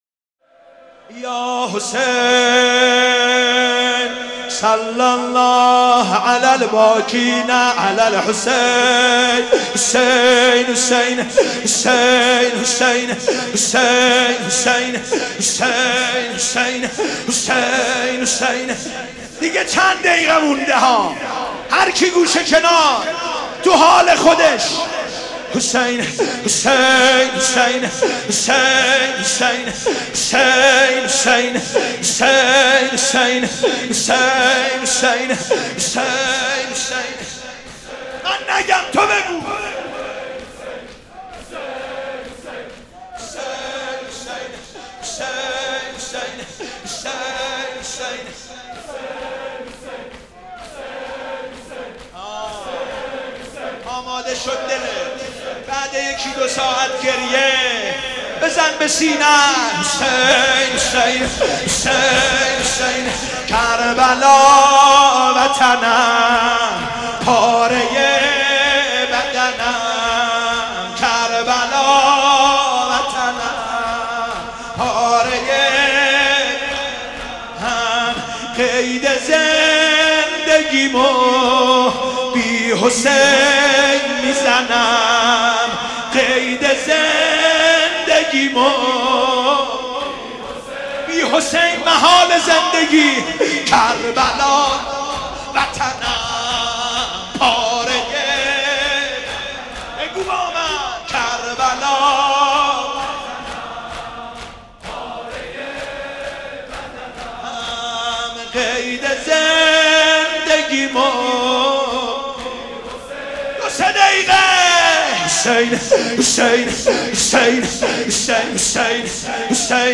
شور - کربلا وطنم